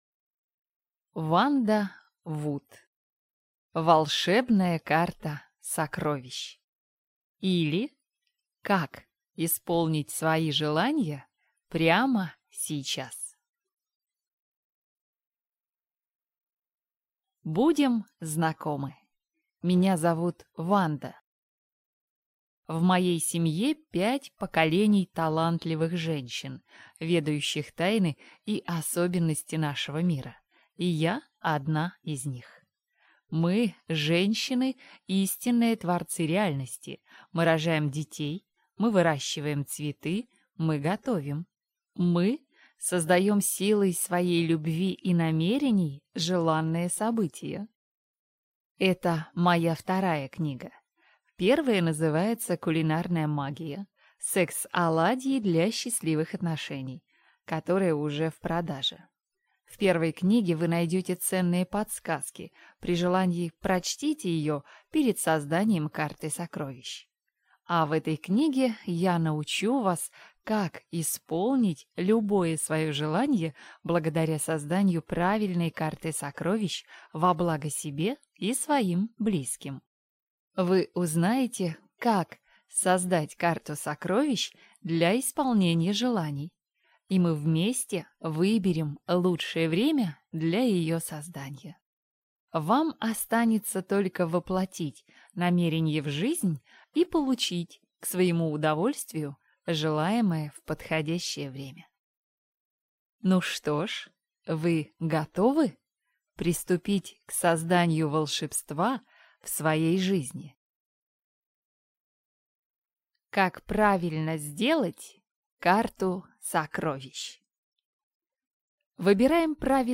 Аудиокнига Волшебная карта сокровищ, или Как исполнить свои желания прямо сейчас | Библиотека аудиокниг